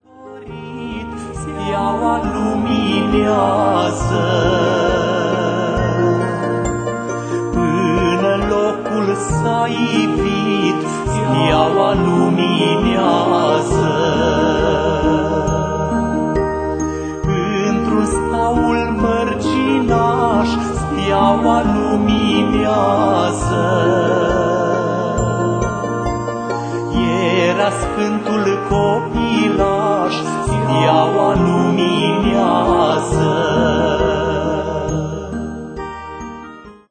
colinde